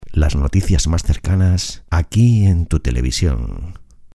Locución gratuita para programas de televisión. Promocional sobre noticias locales.
noticias_cercanas_locucion_television_locutortv.mp3